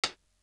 Simmons Hat.wav